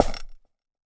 Step3FX.wav